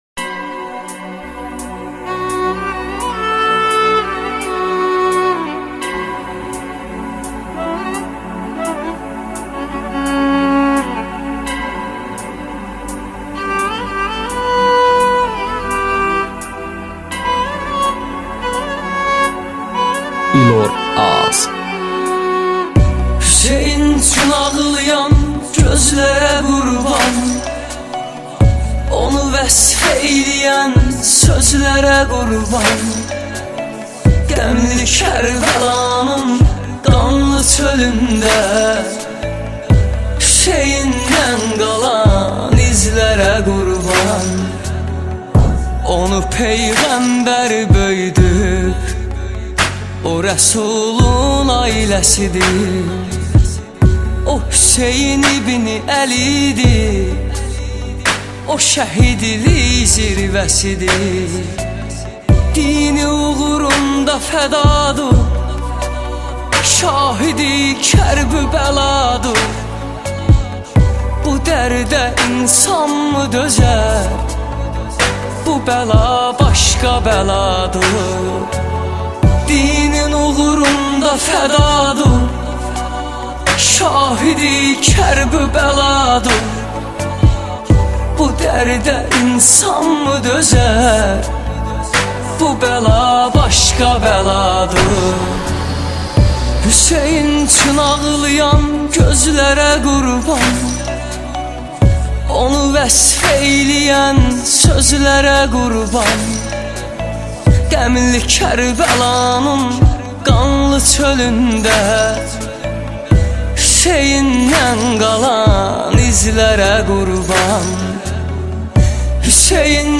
موزیک ترکی آذربایجانی